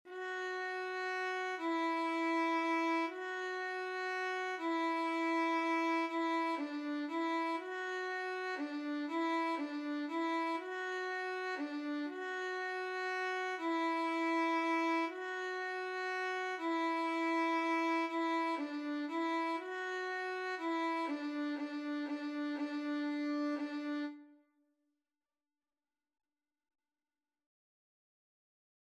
3/4 (View more 3/4 Music)
D5-F#5
Violin  (View more Beginners Violin Music)
Classical (View more Classical Violin Music)